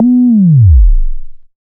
Naughty Sweep.wav